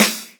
osu-logo-downbeat.wav